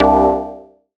Synth Stab 08 (C).wav